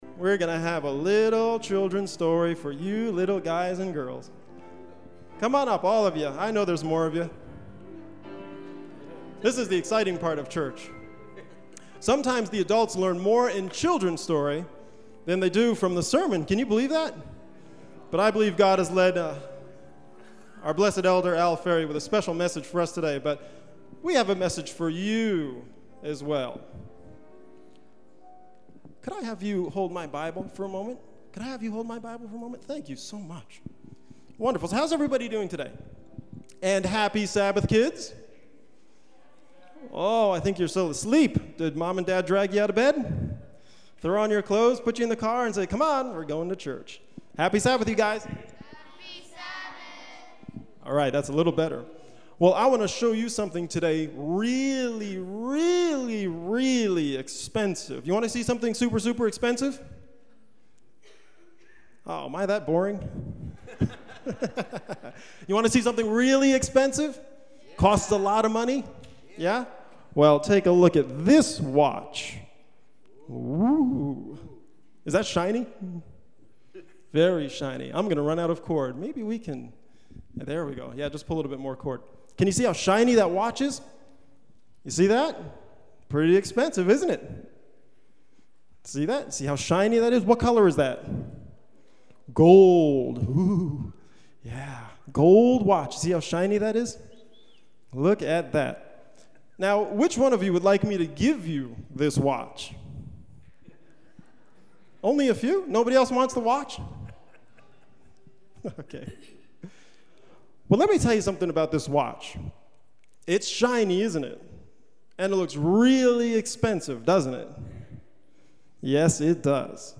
children story